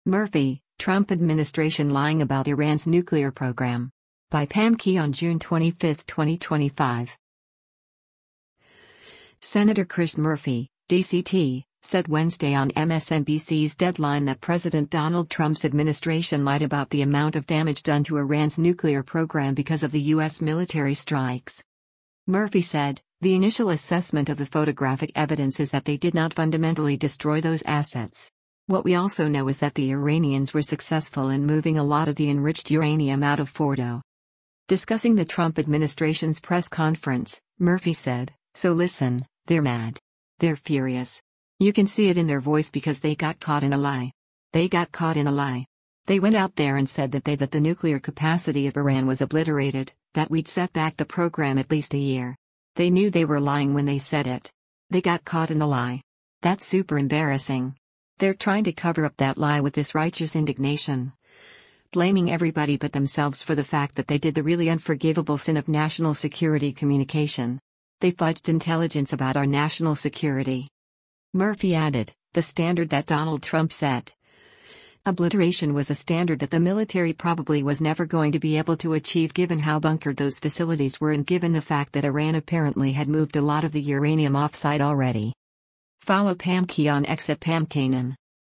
Senator Chris Murphy (D-CT) said Wednesday on MSNBC’s “Deadline” that President Donald Trump’s administration lied about the amount of damage done to Iran’s nuclear program because of the U.S. military strikes.